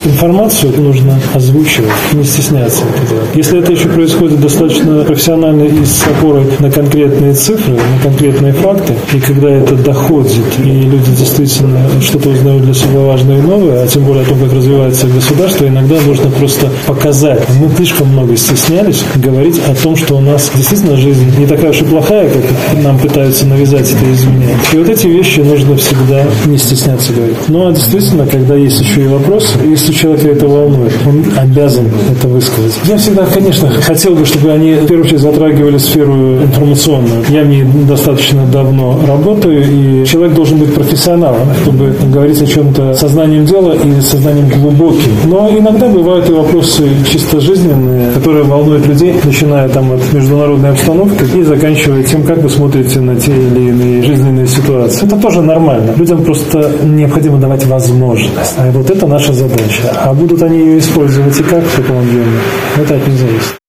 Министр информации в Барановичах встретился с коллективом Локомотивного депо
Встреча прошла в  формате конструктивного диалога.